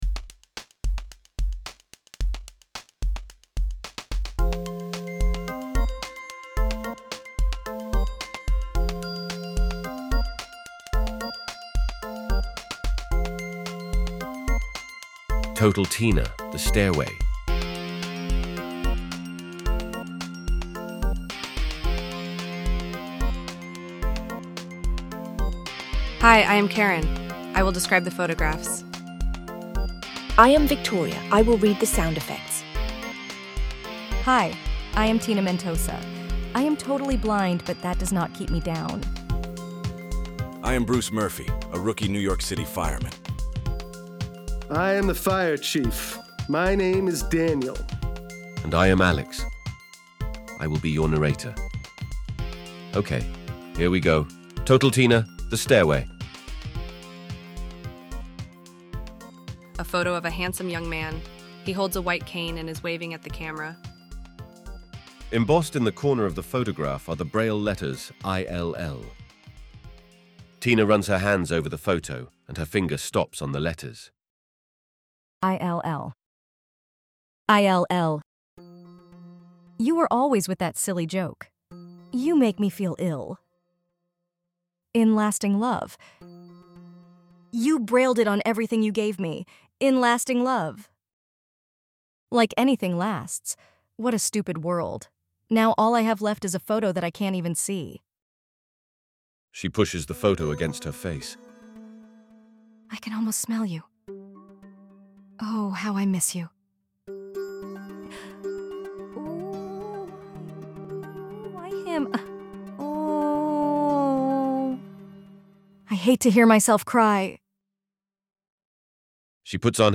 Total Tina Audio Dramas